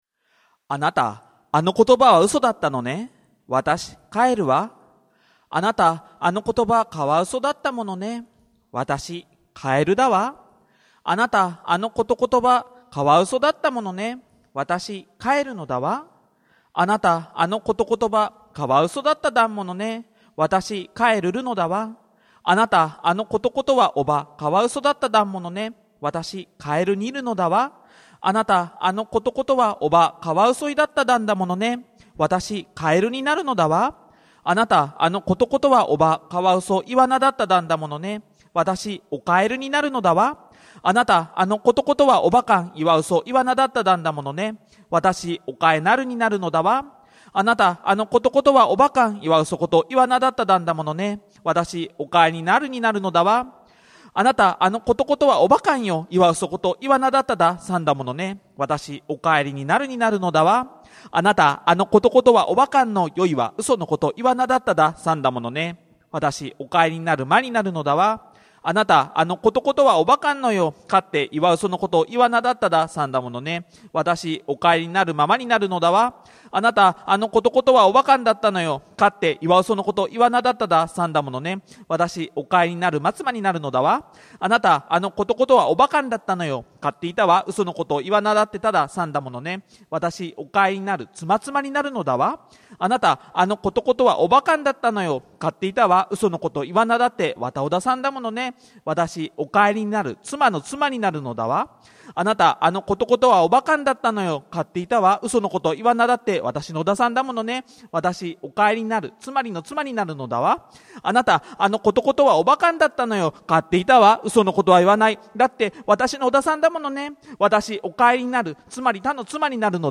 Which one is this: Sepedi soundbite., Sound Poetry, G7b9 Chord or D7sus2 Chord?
Sound Poetry